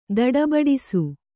♪ daḍabaḍisu